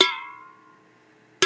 Aluminum Can
Aluminum Can Ding Ring Strike sound effect free sound royalty free Sound Effects